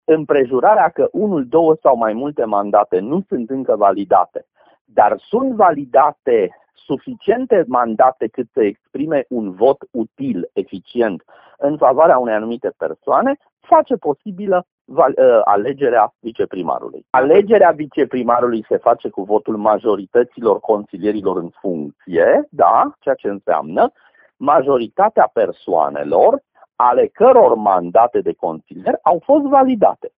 Un avocat timișorean explică modul în care poate fi ales cel de-al doilea viceprimar al municipiului